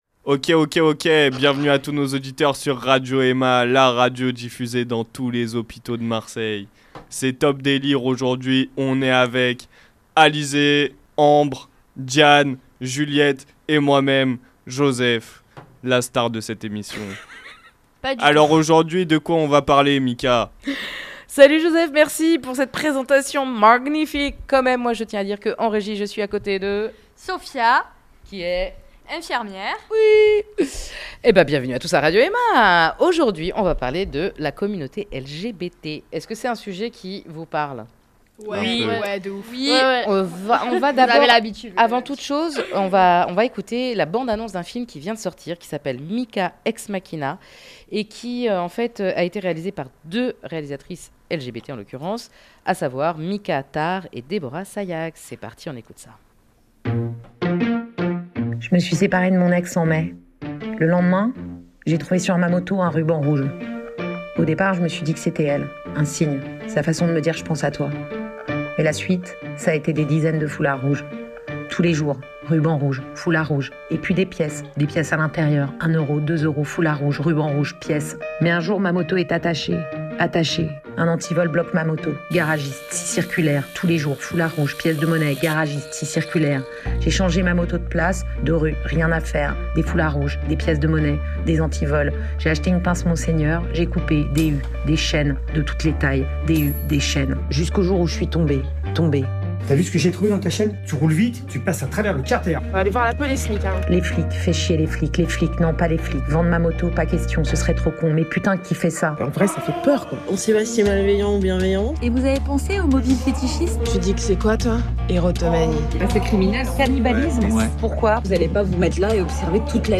L'émission d'aujourd'hui est consacrée aux liens que l'on peut avoir avec la commmunauté LGBT au sens large. À partir de la bande annonce du film MIKA Ex-Machina de Mika Tard et Déborah Saïag, les ados racontent leurs expériences, leurs ressentis et on peut dire que ça vole très haut!
Une émission survoltée qui en vaut le détour!!